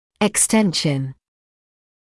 [ɪk’stenʃn][ик’стэншн]распространение (напр. об инфекции); экстензия; вытяжение